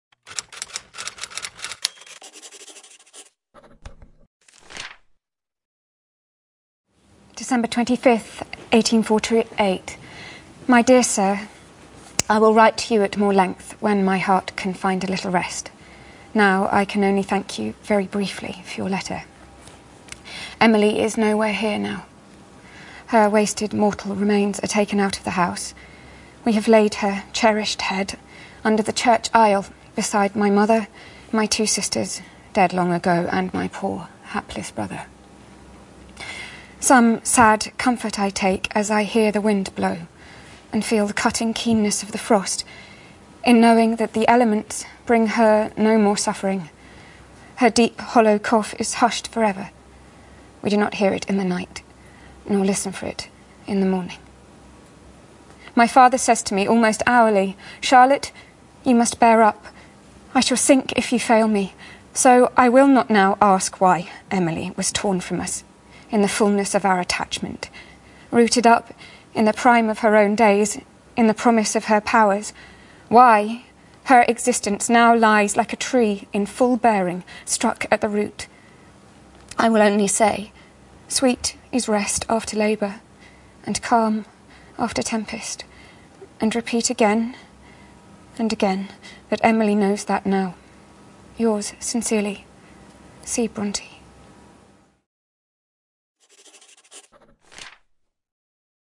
在线英语听力室见信如晤Letters Live 第19期:'露易丝布瑞莉'读信:像一棵果实累累的大树被连根砍断的听力文件下载,《见信如唔 Letters Live》是英国一档书信朗读节目，旨在向向书信艺术致敬，邀请音乐、影视、文艺界的名人，如卷福、抖森等，现场朗读近一个世纪以来令人难忘的书信。